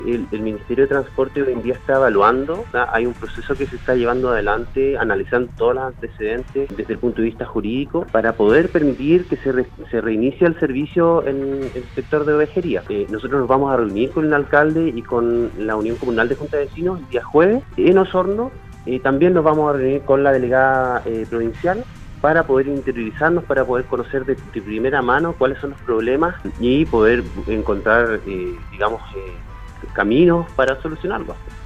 En conversación con Radio Sago, el Seremi de Transportes y Telecomunicaciones de la región de Los Lagos, Pablo Joost, se refirió a la problemática que viven los vecinos de Ovejería en Osorno, producto de la nula presencia del transporte público mayor.